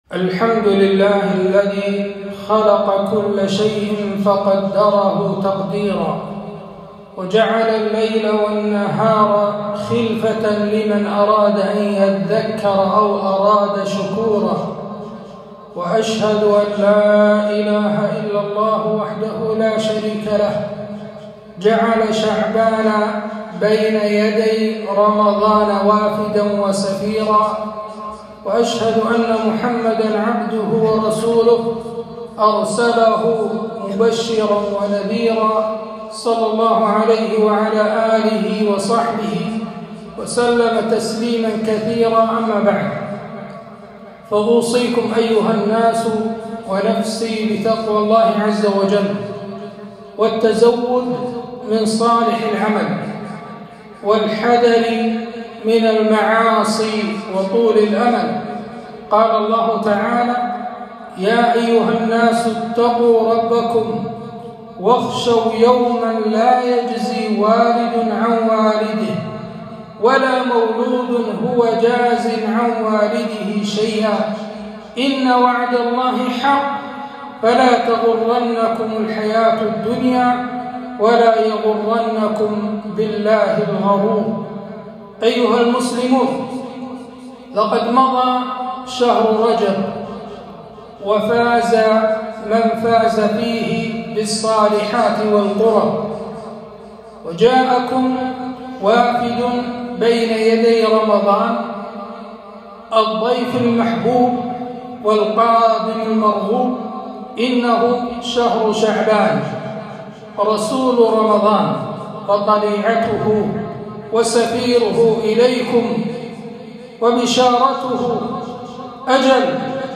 خطبة - شعبان شهر القراء